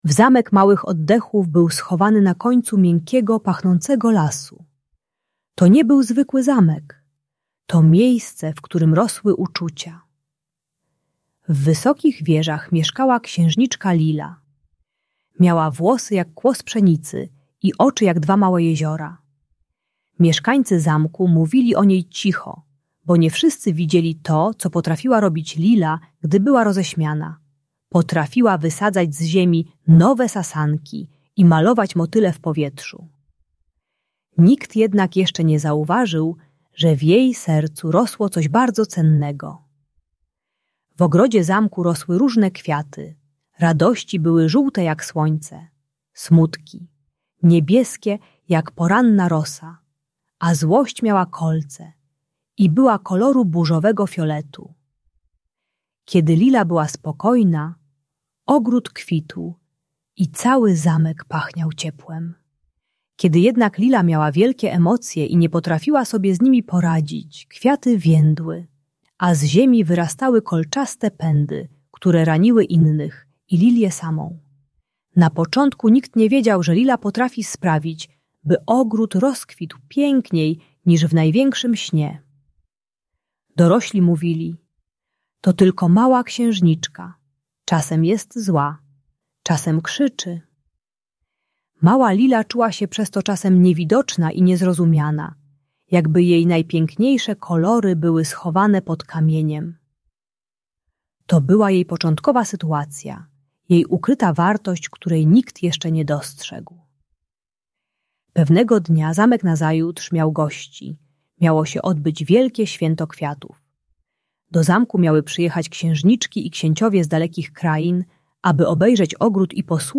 Zamek Małych Oddechów - Bunt i wybuchy złości | Audiobajka